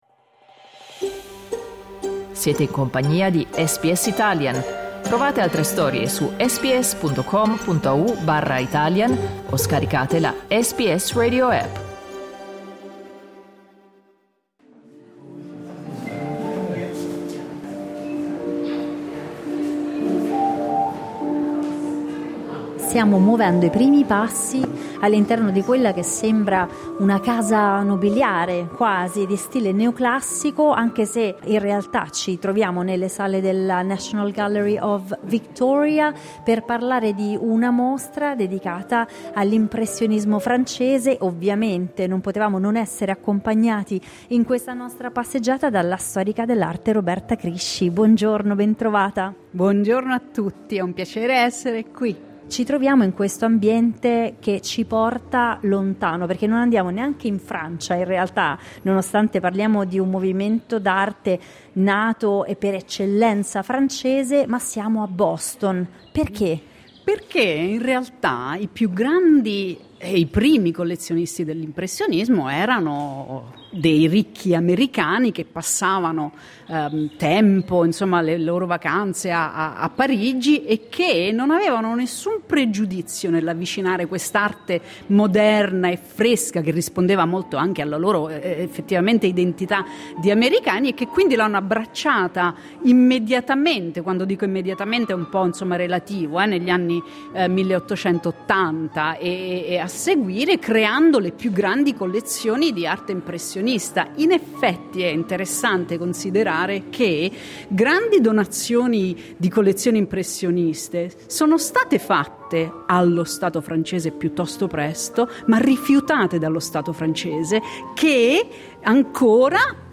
Clicca sul tasto "play" in alto per ascoltare la guida alla mostra in italiano L'allestimento immersivo della mostra, che richiama le grandi dimore americane di fine XIX secolo, è parte integrante del racconto della storia del movimento.